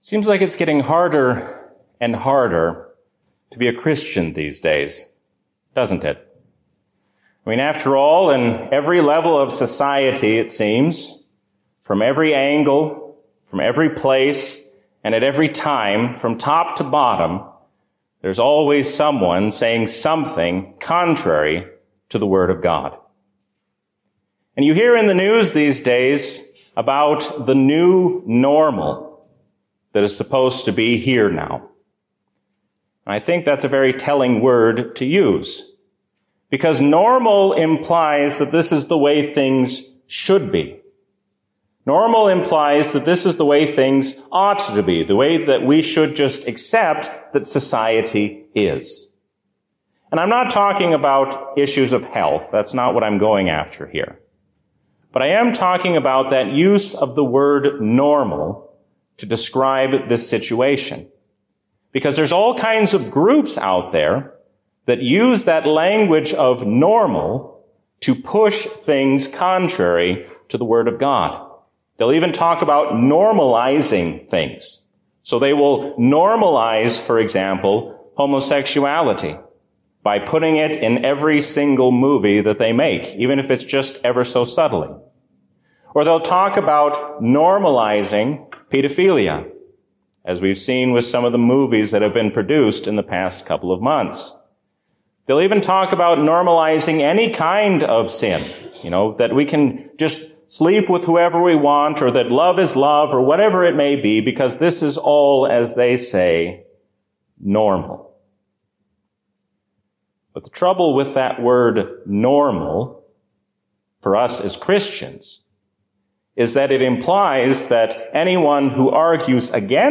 A sermon from the season "Trinity 2024." Let us not be double minded, but single minded in our devotion toward God.